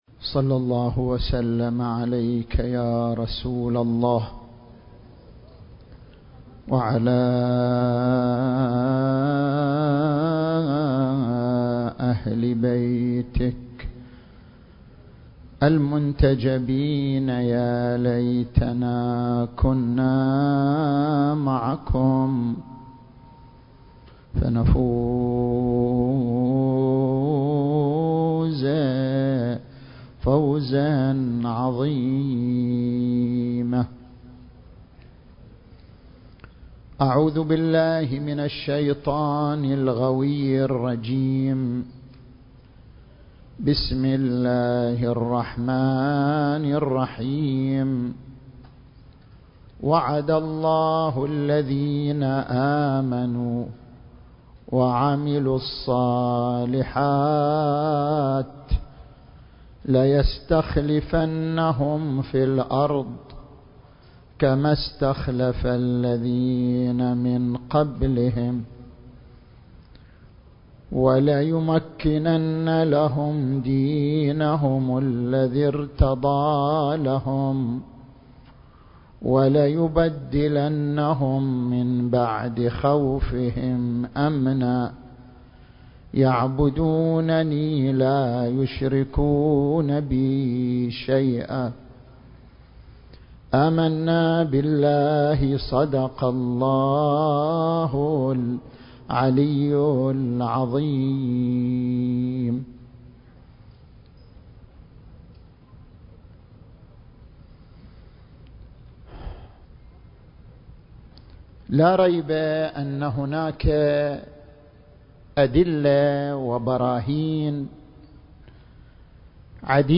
المكان: المركز الإسلامي بأمريكا التاريخ: 2025